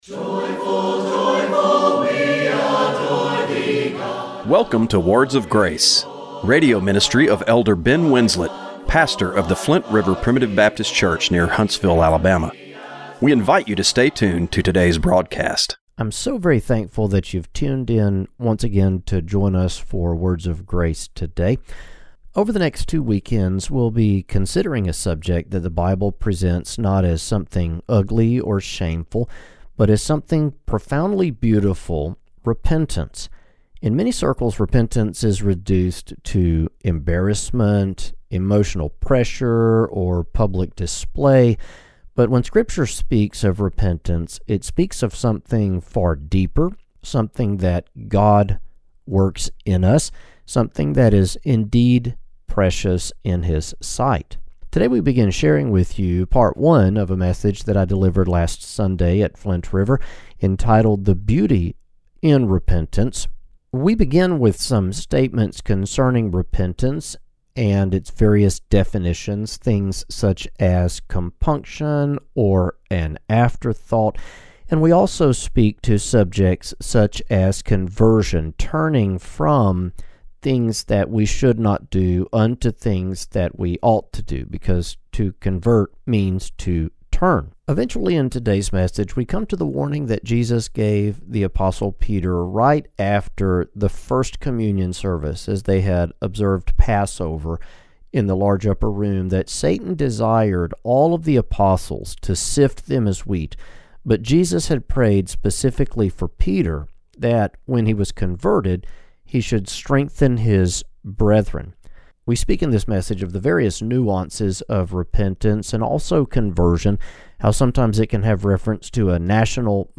Radio broadcast for January 11, 2026.